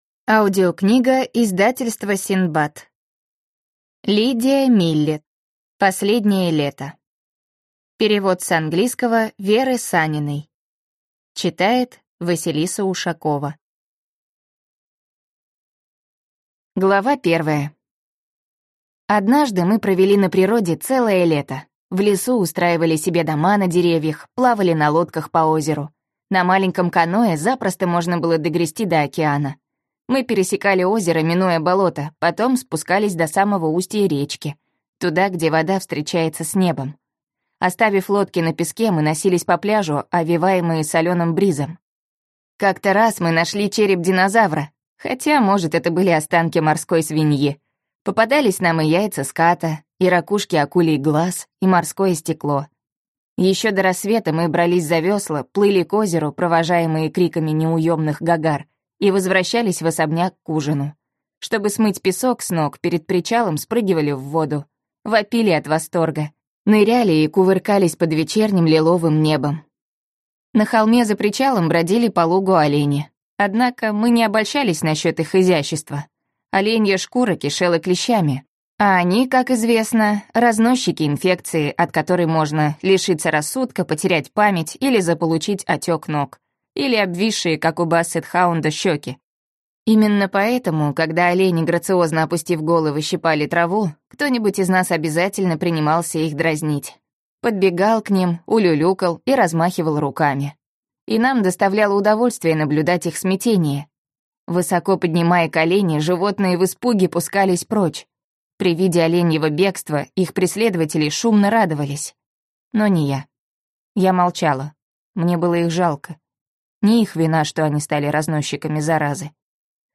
Аудиокнига Последнее лето | Библиотека аудиокниг